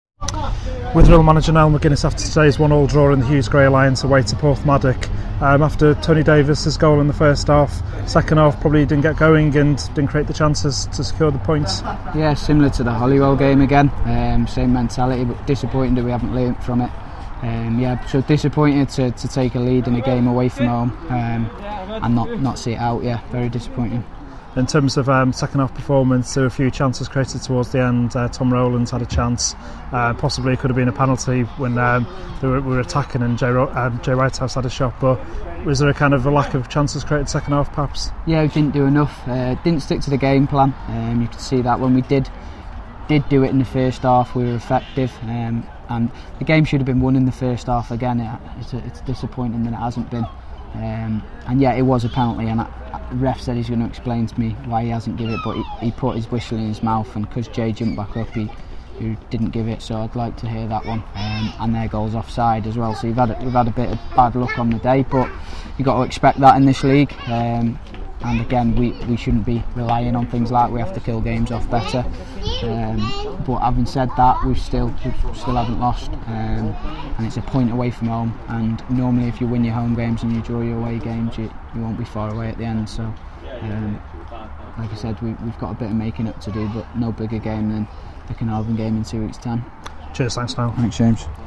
Post match reaction
Interview